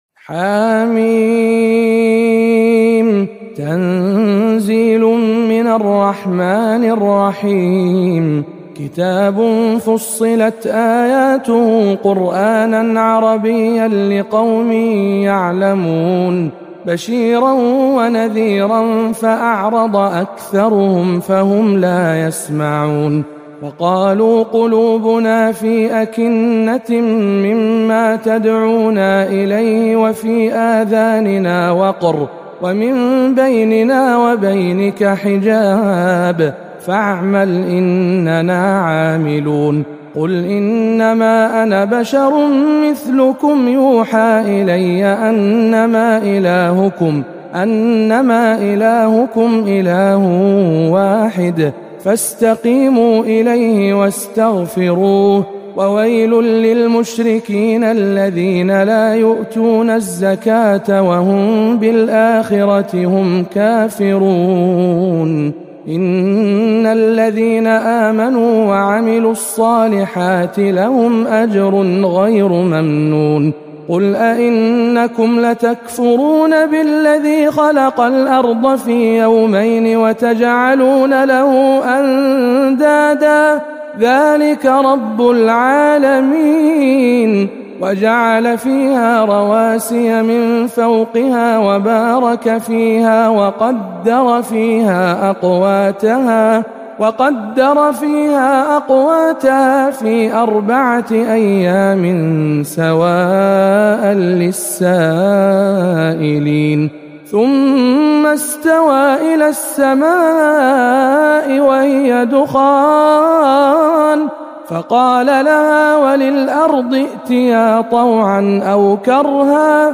سورة فصلت بجامع معاذ بن جبل مكة المكرمة